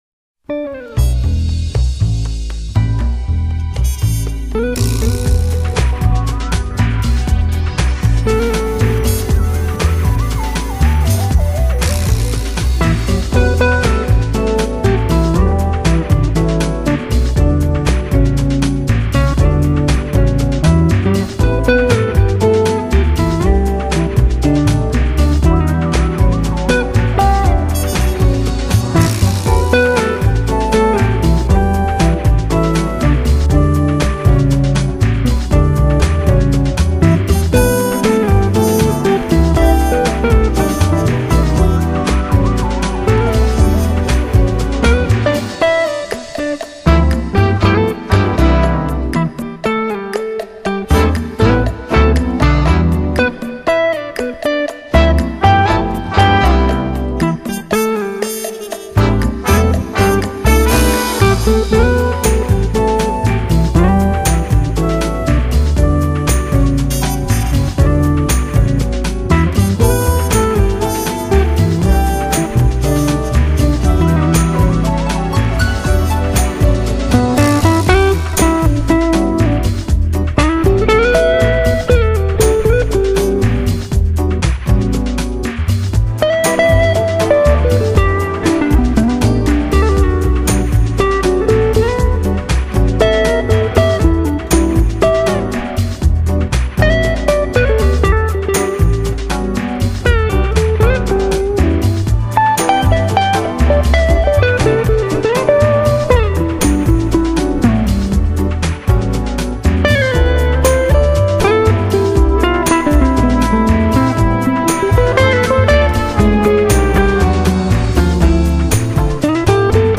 在专辑当中，他负责吉他的演 奏，而他的电吉他的技艺一点也不比同领域的那些吉他手逊色。